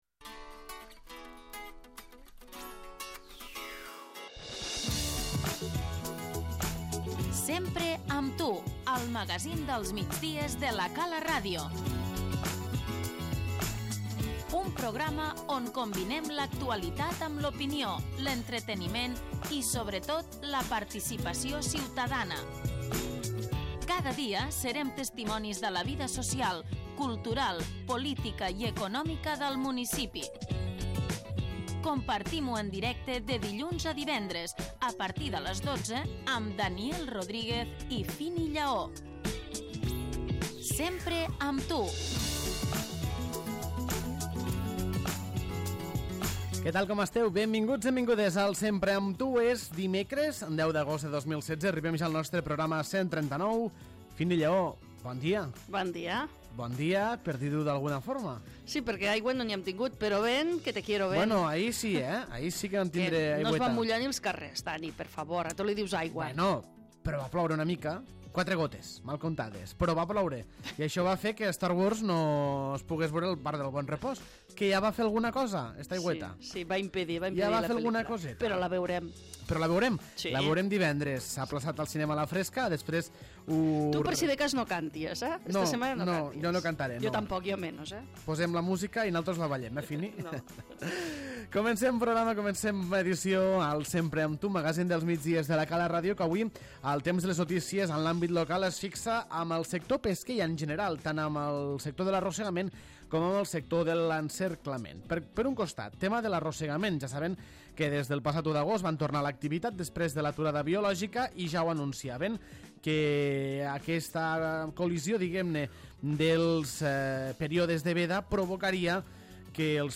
LES NOTÍCIES - Tot i que milloren les captures, el peix s'està venent a preus molt baixos L'ENTREVISTA Aquesta tarda tornen els contacontes d'estiu a la plaça Nova.